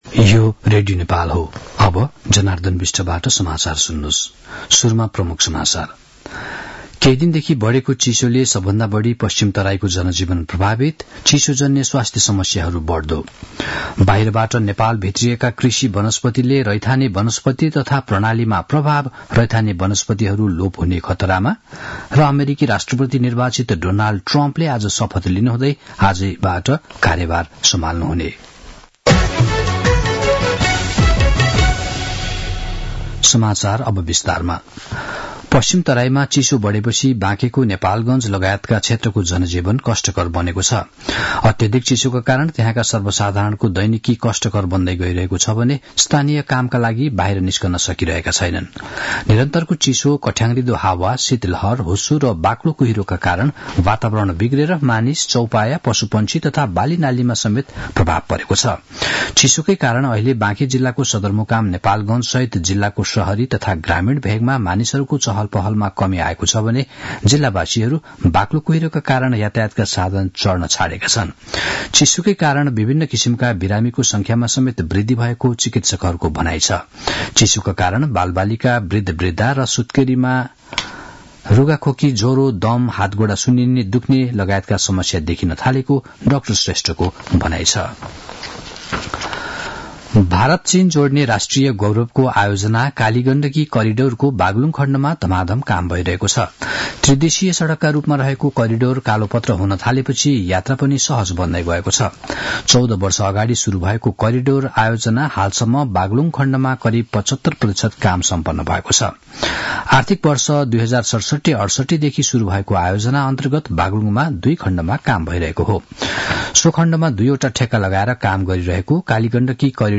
दिउँसो ३ बजेको नेपाली समाचार : ८ माघ , २०८१
3-pm-news-1-7.mp3